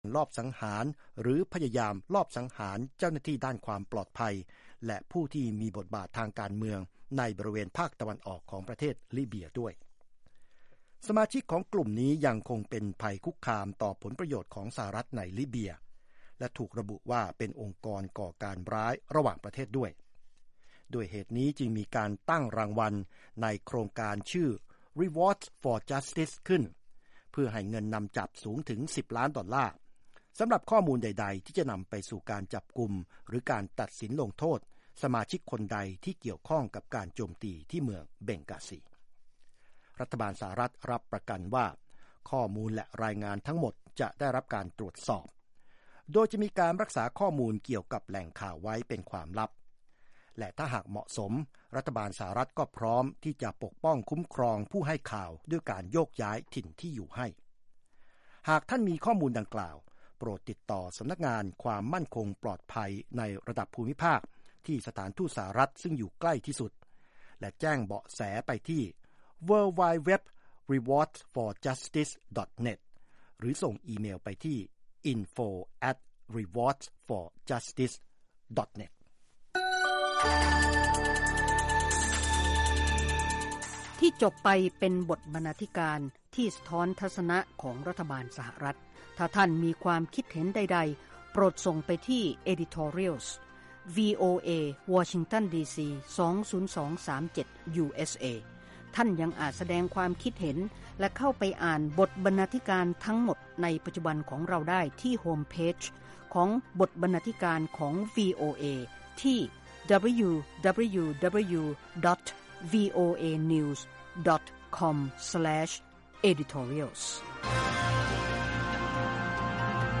ข่าวสดสายตรงจากวีโอเอ ภาคภาษาไทย 6:00 – 6:30 น. จันทร์ที่ 4 พ.ค 2558